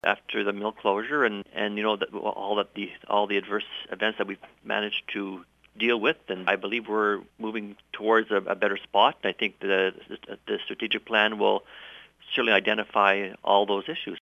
Mayor Michael Shea says this funding will help the town to grow.